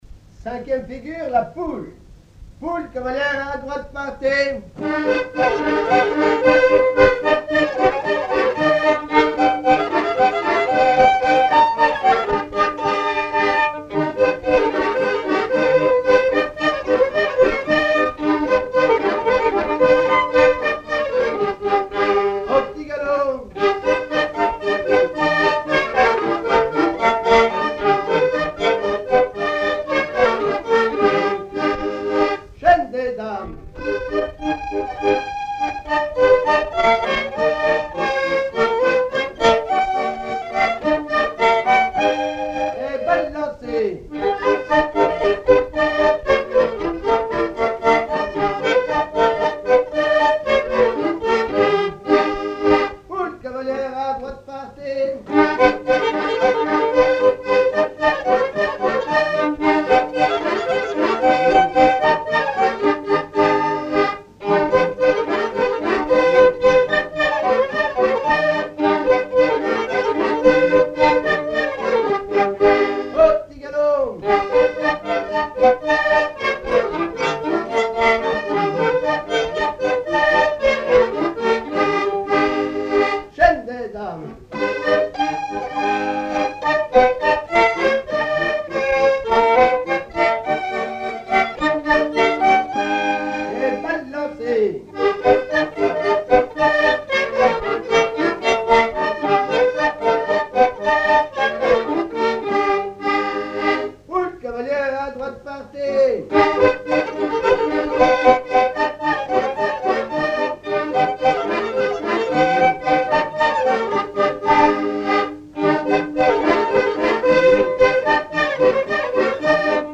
Rochetrejoux
danse : quadrille : poule
Pièce musicale inédite